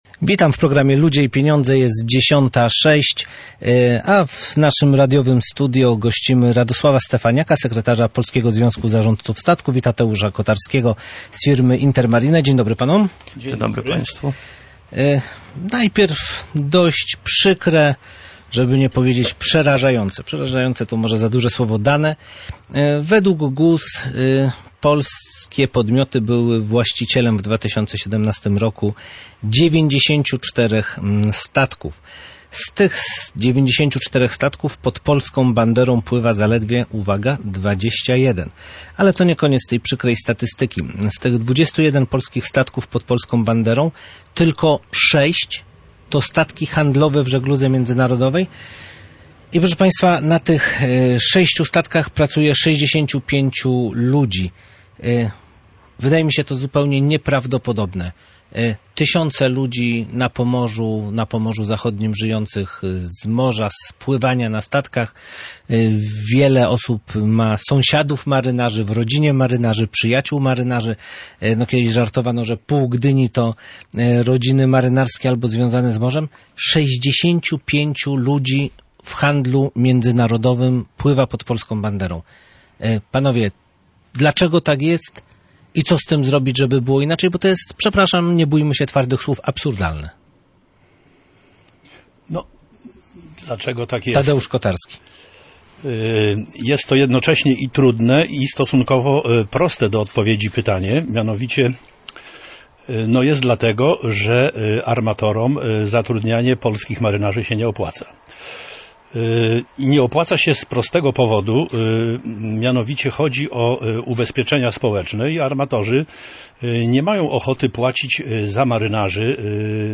O przyczynach takiego stanu rozmawiali goście